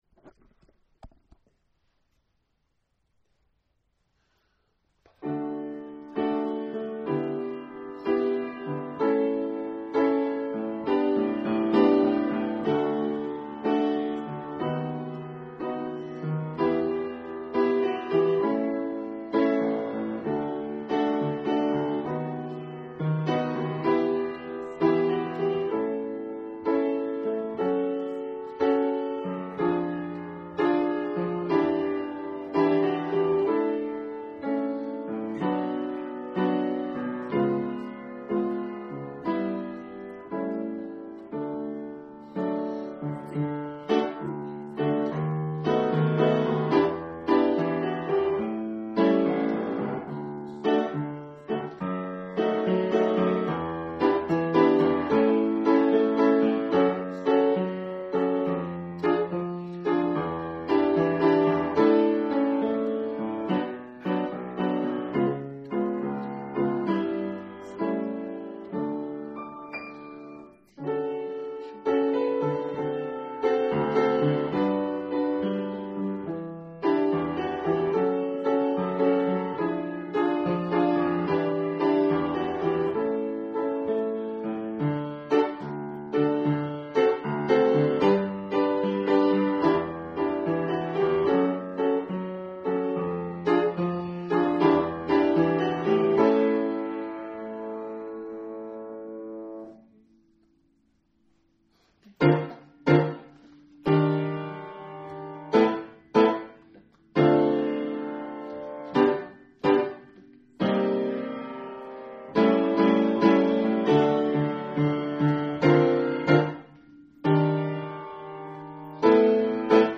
...et juste avec la musique :